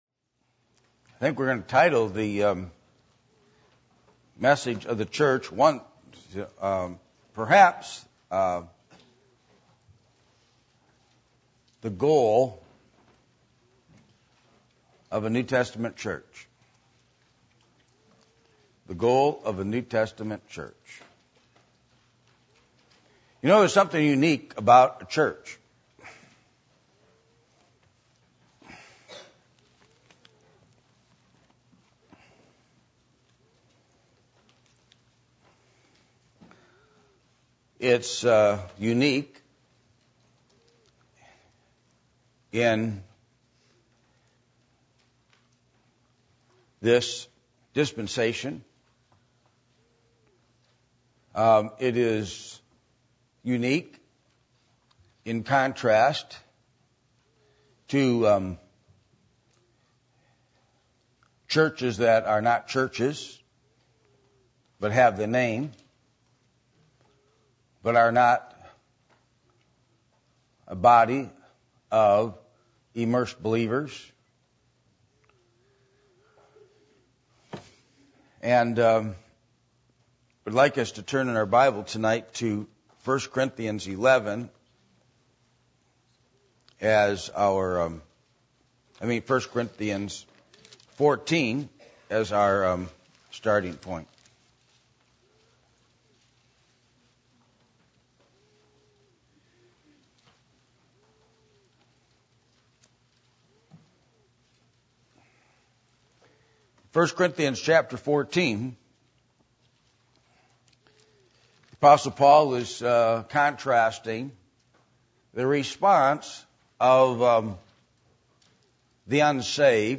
1 Corinthians 14:23-25 Service Type: Sunday Evening %todo_render% « The Cross Of Christ Is Victory